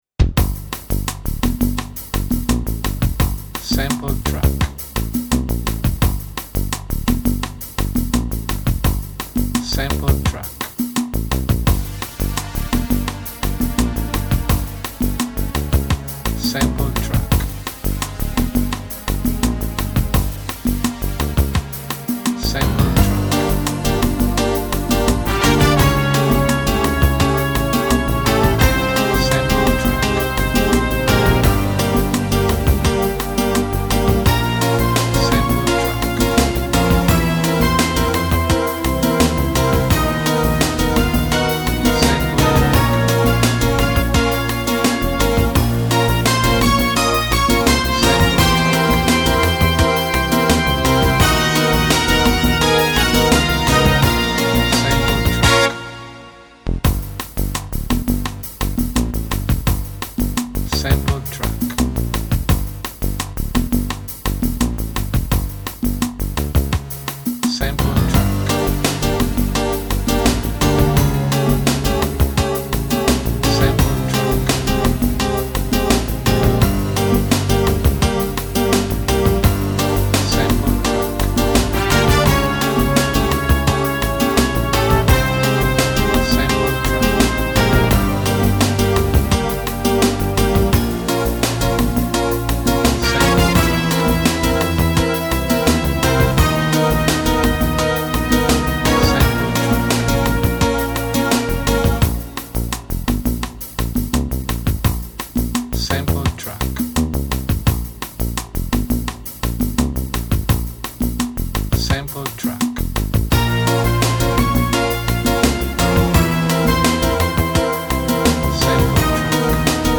Competizione /Affanno